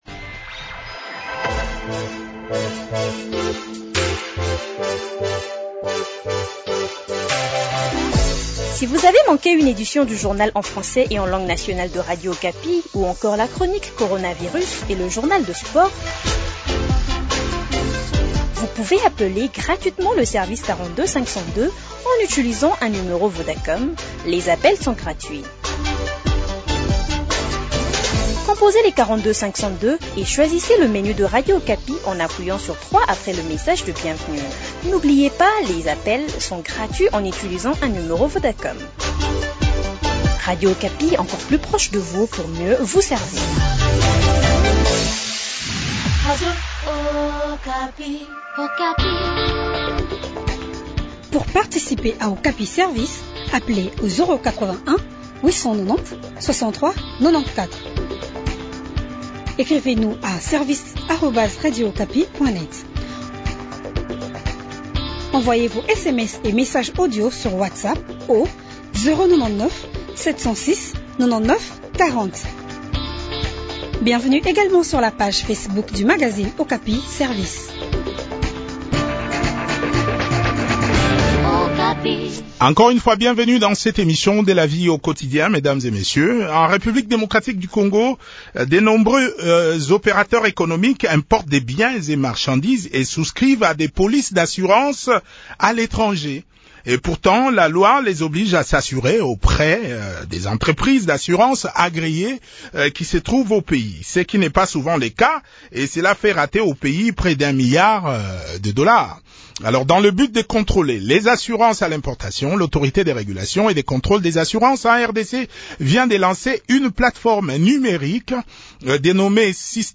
inspecteur a la DGDA a aussi participé à cet entretien.